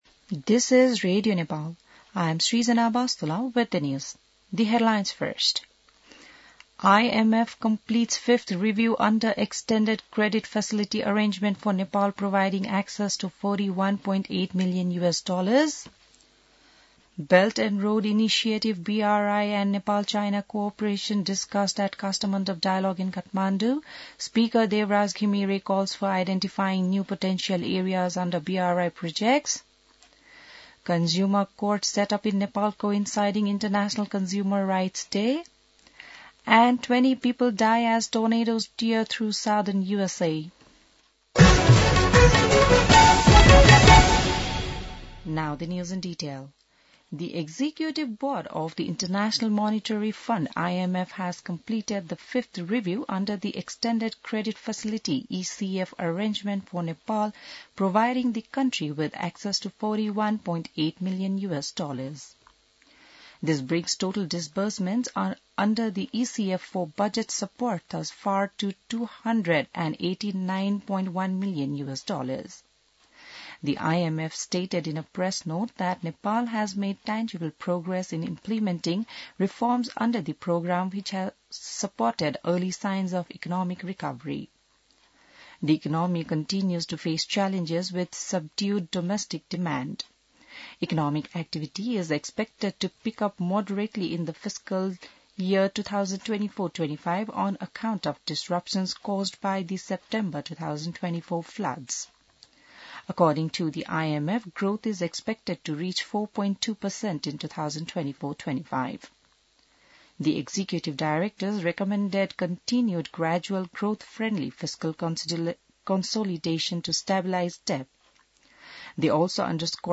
बिहान ८ बजेको अङ्ग्रेजी समाचार : ३ चैत , २०८१